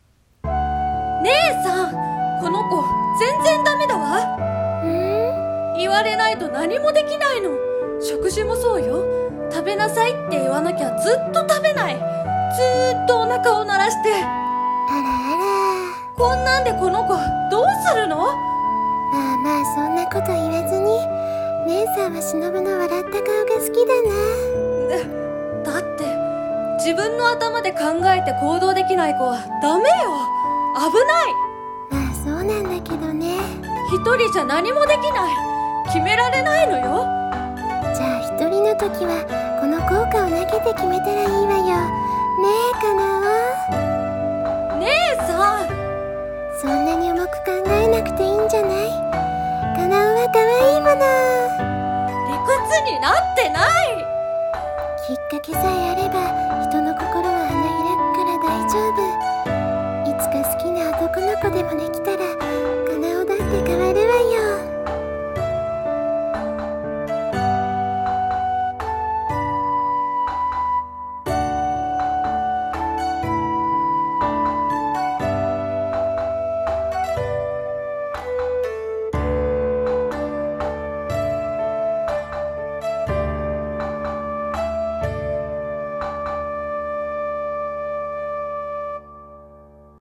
【鬼滅の刃】胡蝶姉妹【声劇台本】